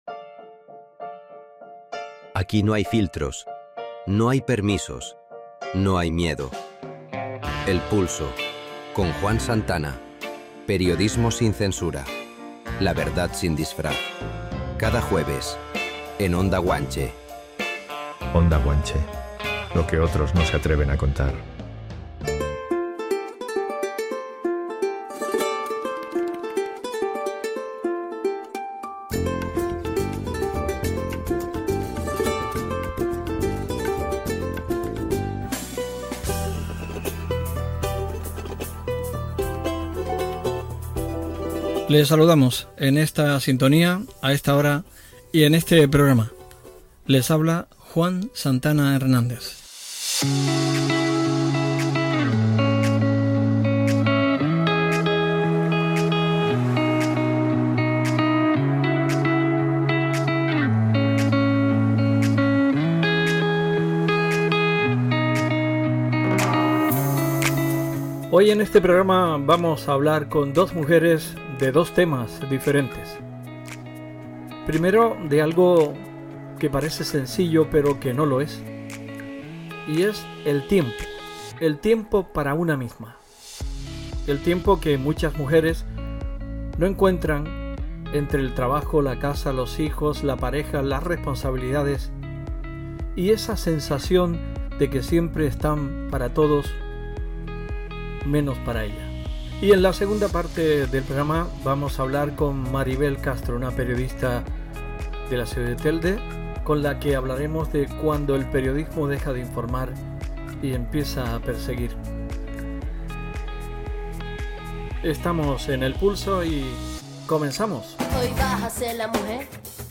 El Pulso, el espacio radiofónico que se emite cada jueves en Onda Guanche, con un programa dividido en dos bloques tan distintos como necesarios: el autocuidado femenino y el debate sobre los juicios paralelos en la prensa local.